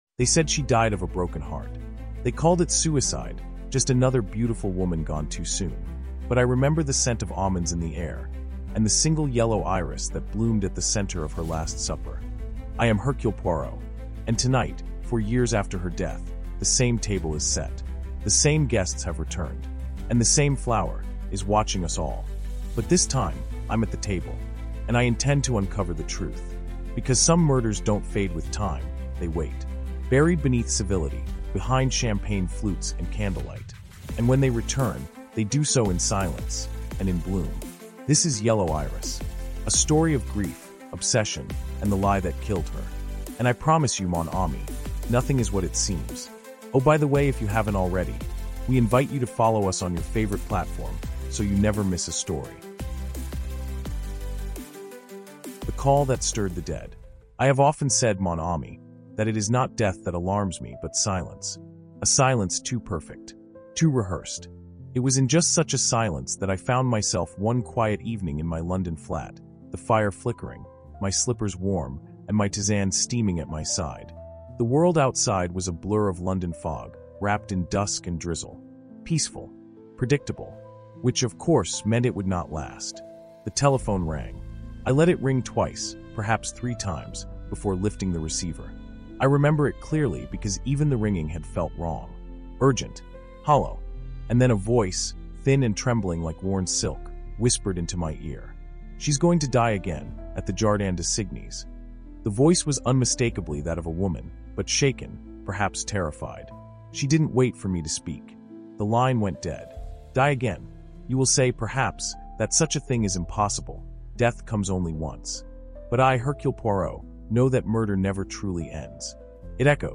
Told in gripping first-person narration, this five-chapter psychological thriller follows the famed detective as he's drawn into a macabre dinner party—an exact reenactment of the night a woman named Iris Russell died from cya